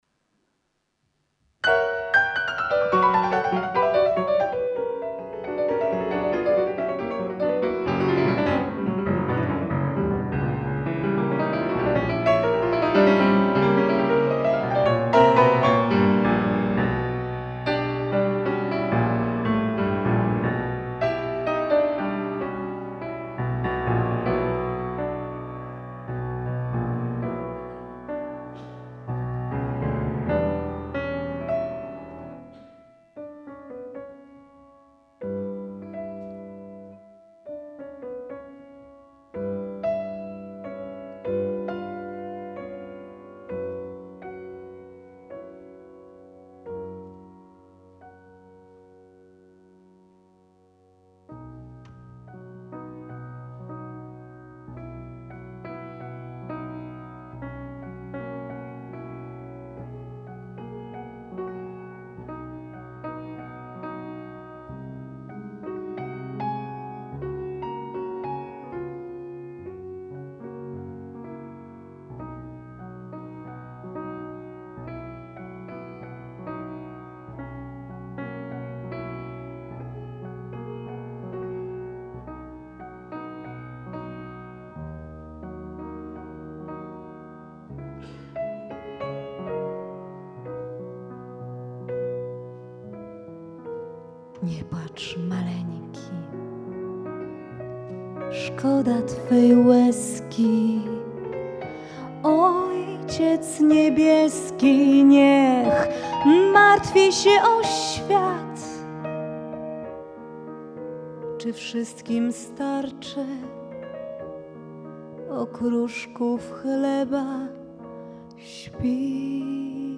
Gra na fortepianie i śpiewa polska pianistka